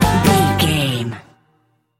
Ionian/Major
acoustic guitar
banjo
bass guitar
drums
Pop Country
country rock
bluegrass
happy
uplifting
driving
high energy